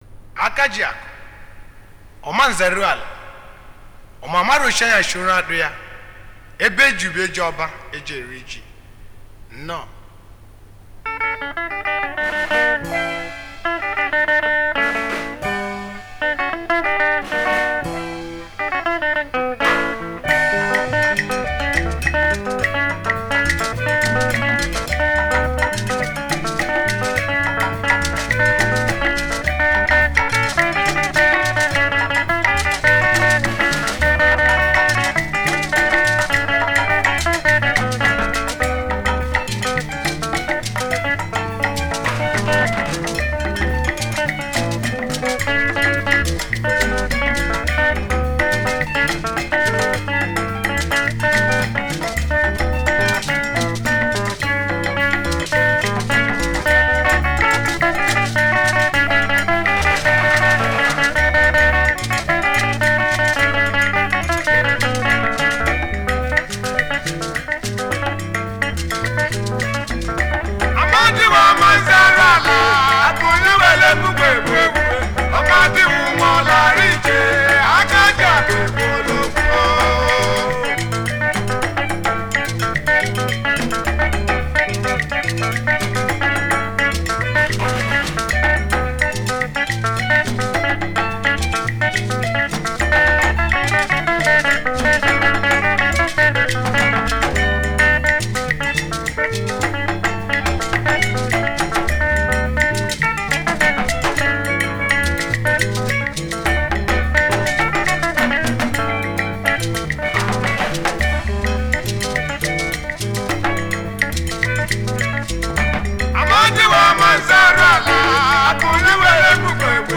Highlife Music
Nigerian Igbo highlife music icon, singer and performer